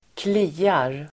Uttal: [²kl'i:ar]